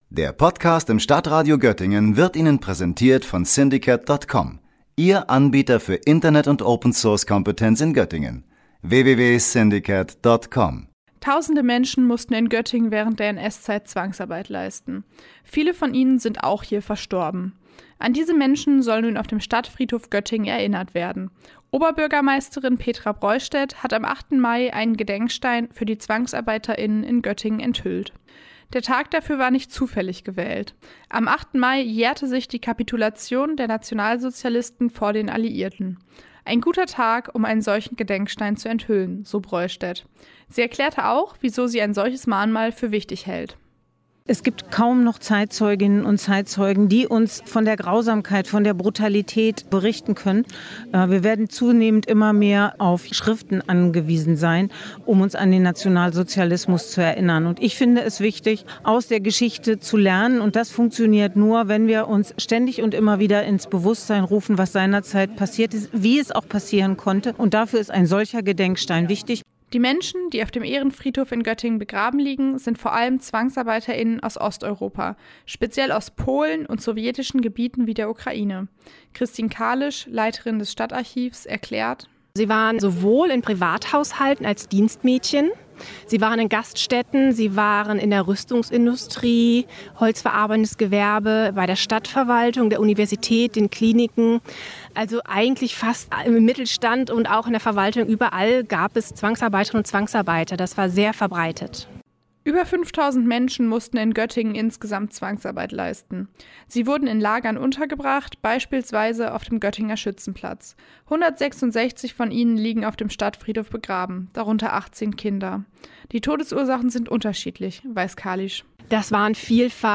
O-Ton 1, Petra Broistedt, 25 Sekunden